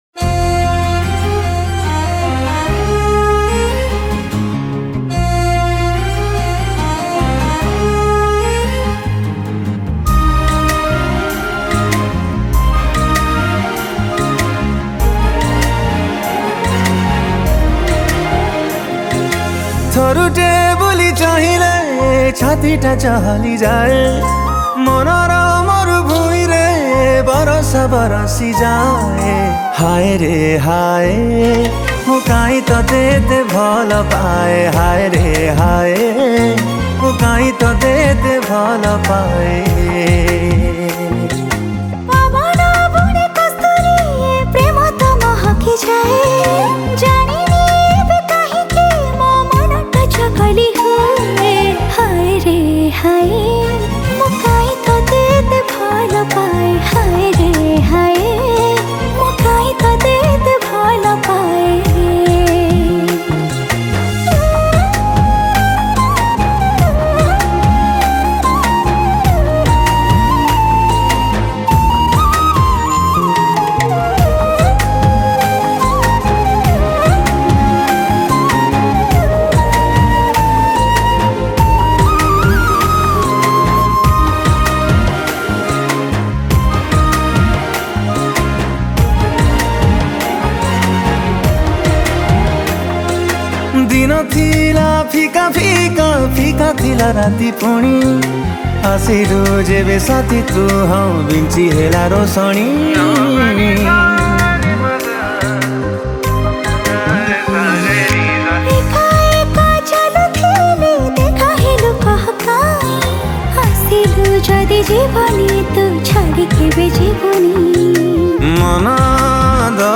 Keyboard
Flute